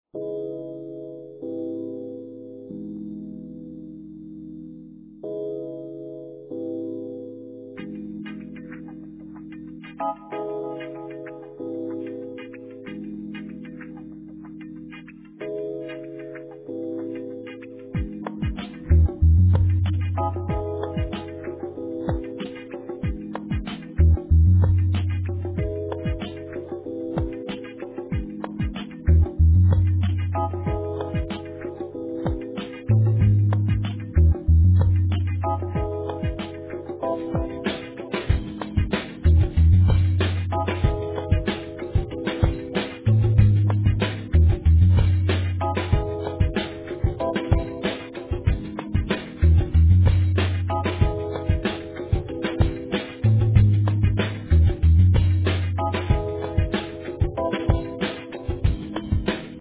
Sick techno song